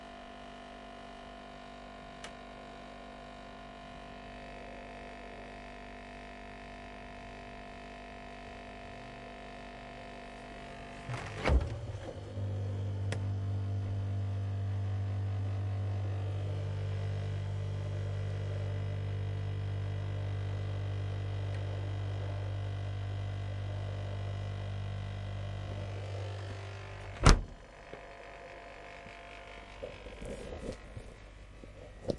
foley " 机器
描述：在较低的音量下，这个声音（这是一个工业制冷机组的声音）听起来就像一个遥远的因素在夜间嗡嗡作响；或者甚至可能是一辆汽车的机舱。这个样本应该是很容易循环的。
Tag: 冰箱行业 哼着